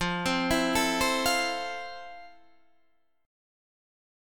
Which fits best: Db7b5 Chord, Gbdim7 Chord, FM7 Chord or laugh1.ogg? FM7 Chord